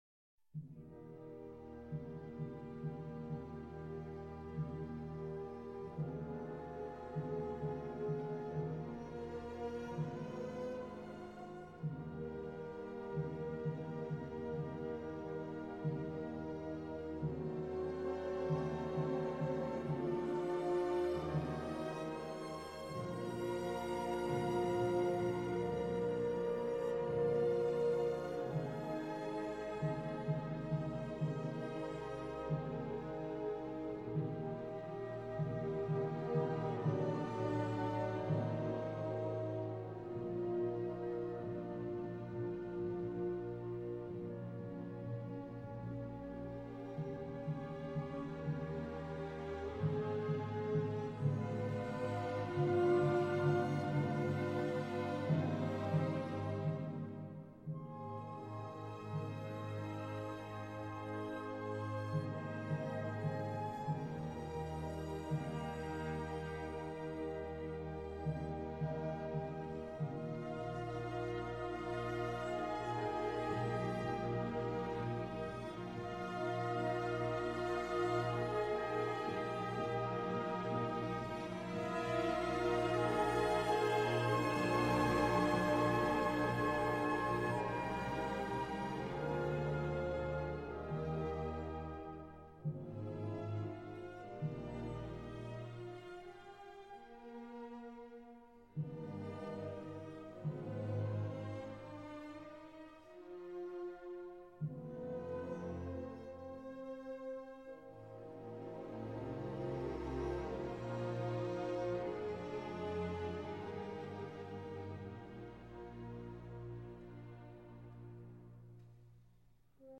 for chorus and orchstra